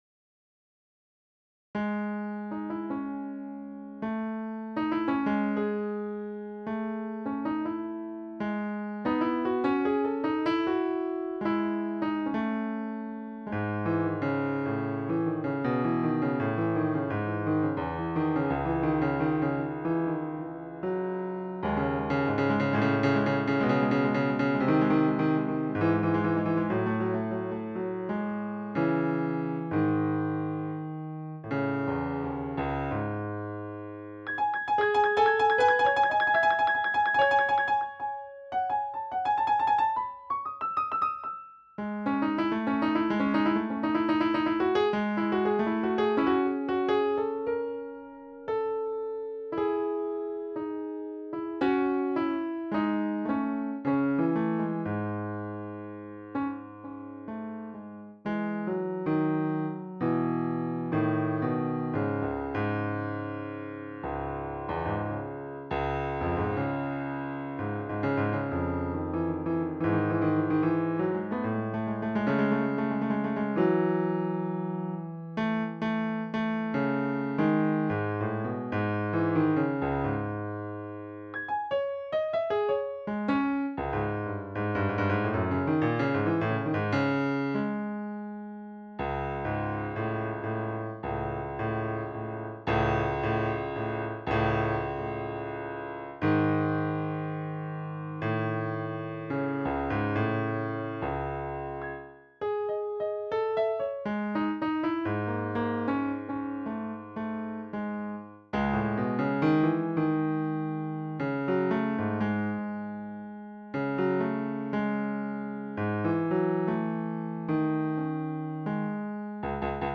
Posted in Classical, Piano Pieces Comments Off on